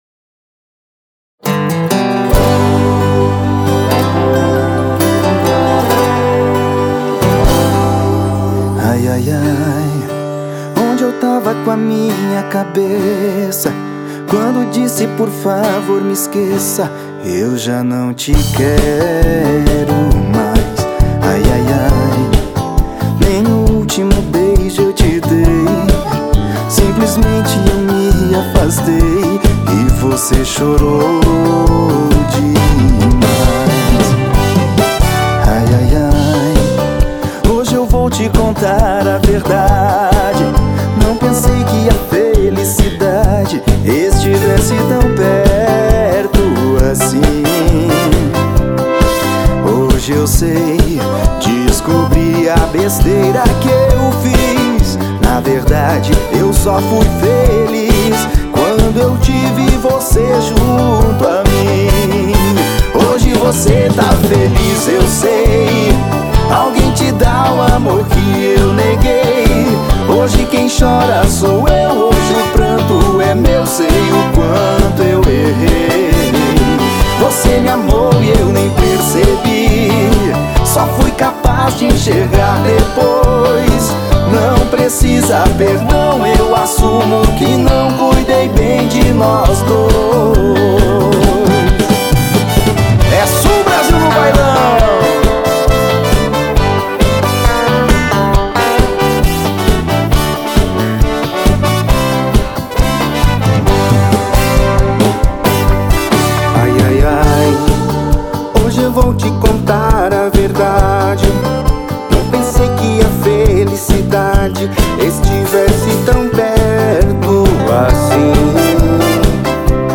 EstiloRegional